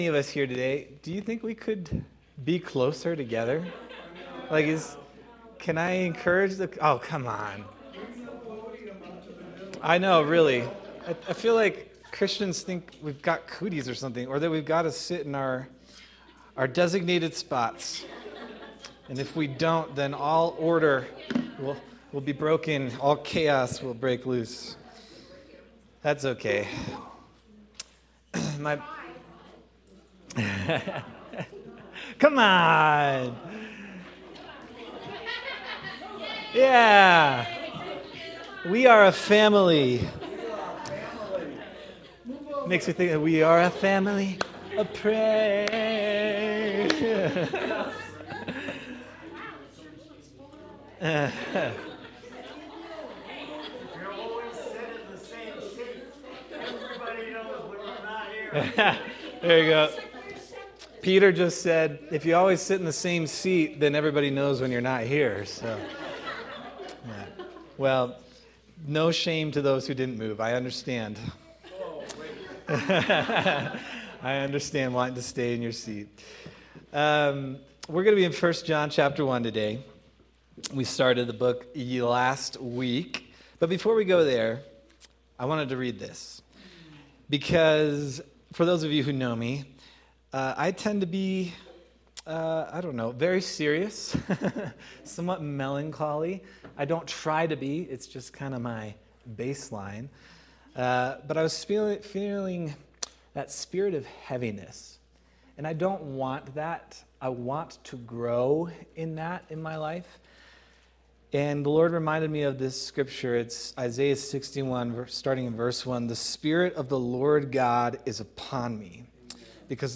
January 11th, 2026 Sermon